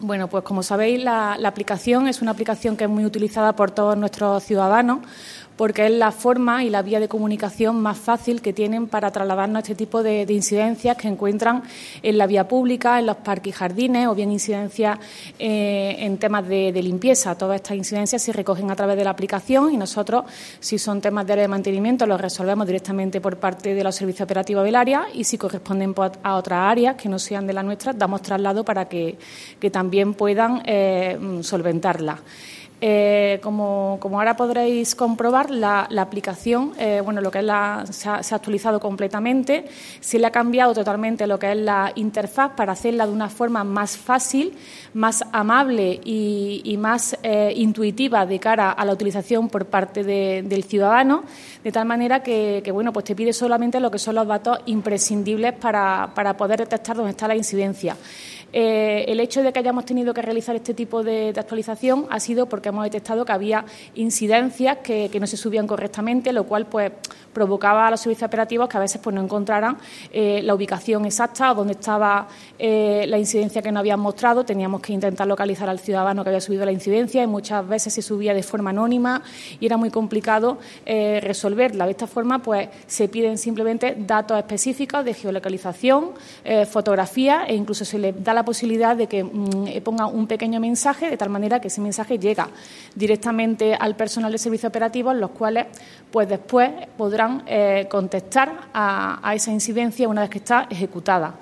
Y precisamente para poder continuar con ese cometido, el Área de Mantenimiento del Ayuntamiento de Antequera ha llevado a cabo una actualización completa de dicha aplicación tal y como ha confirmado en rueda de prensa la teniente de alcalde delegada de Mantenimiento, Teresa Molina.
Cortes de voz